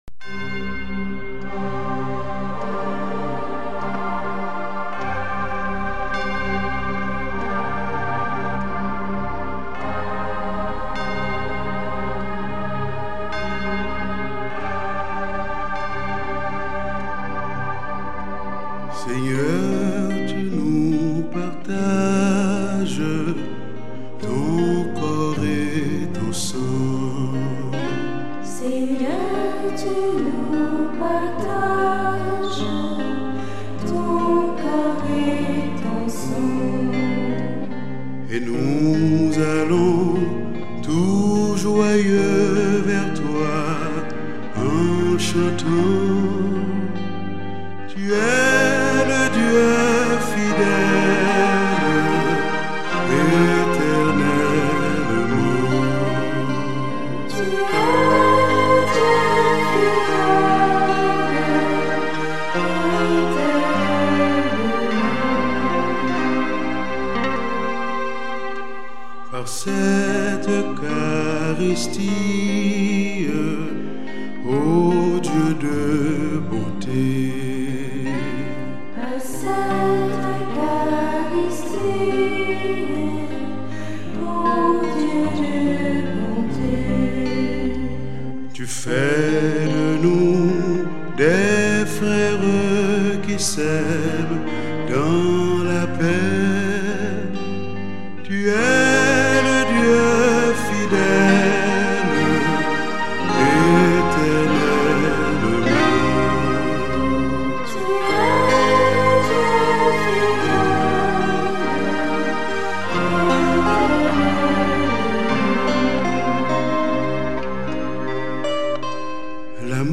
CHANTS D'ÉGLISE